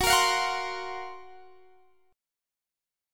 Listen to F#M7b5 strummed